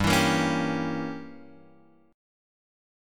G Diminished 7th